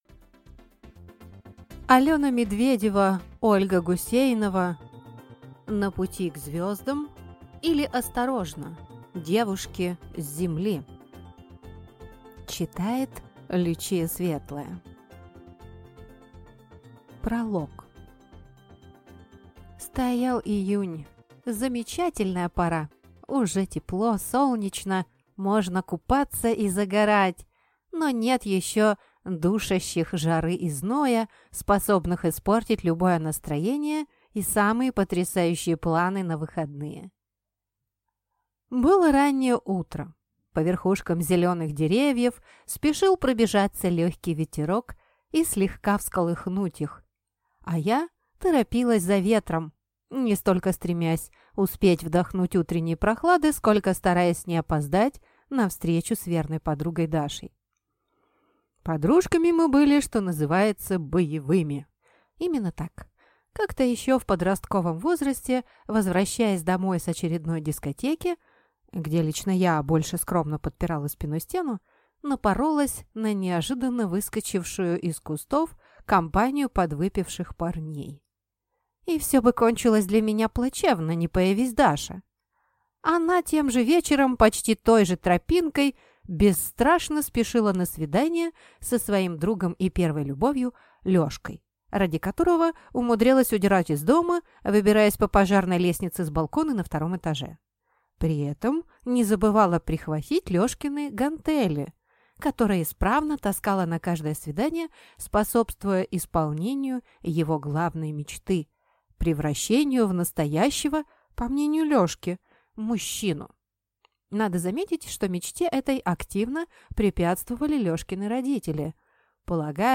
Аудиокнига На пути к звездам, или Осторожно: девушки с Земли!